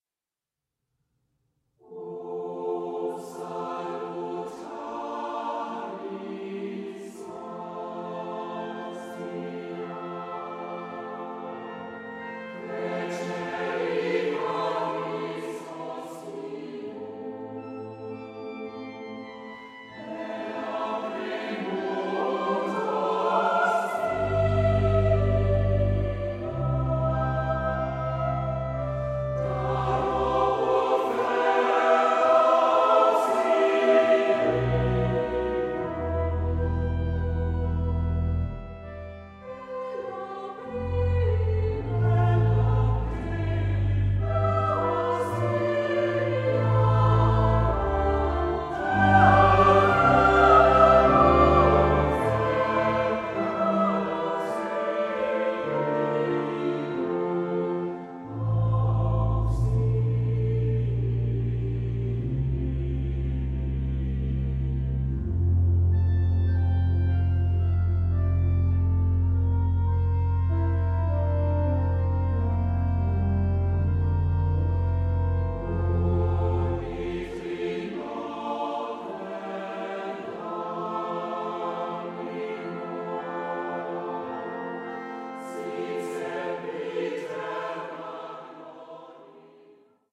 SATB (4 voices mixed) ; Full score.
Sacred. Motet.